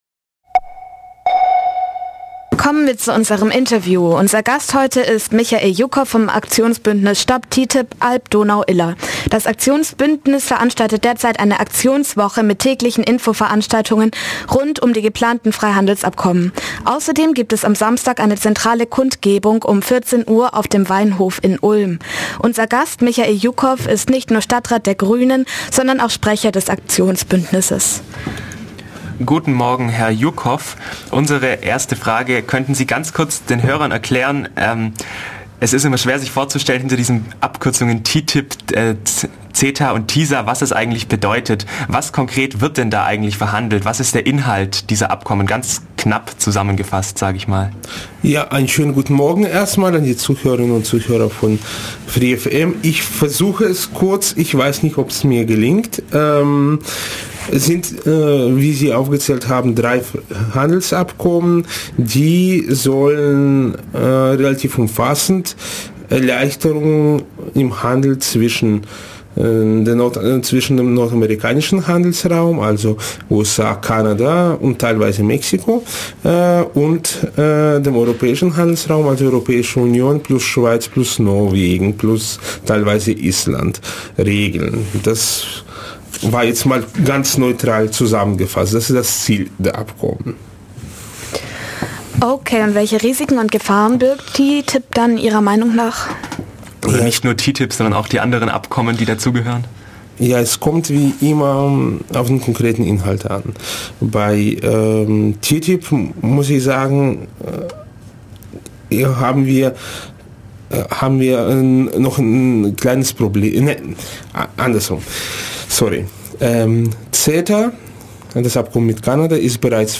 Interview mit Michael Joukov (Stop TTIP)
Am Donnerstag, den 9.10., war der Grünen-Stadtrat Michael Joukov bei uns zu Gast.
interviewttip.mp3